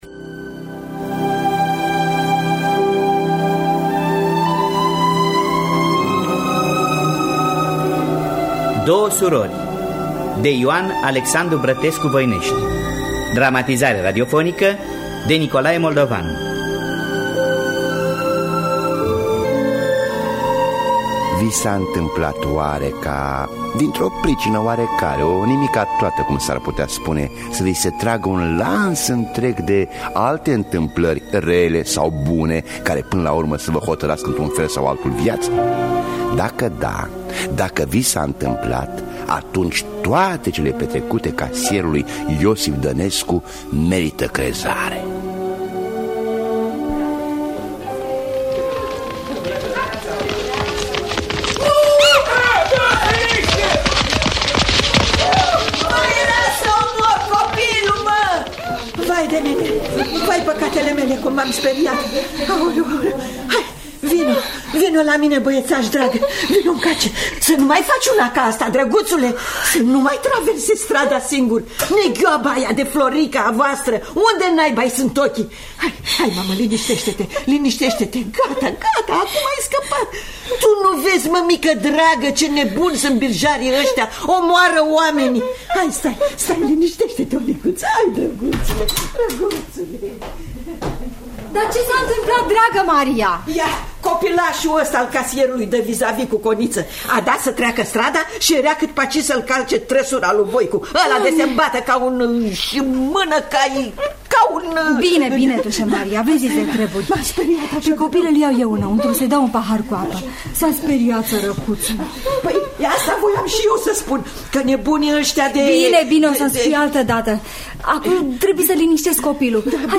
Dramatizarea radiofonică de Nicolae Moldovan.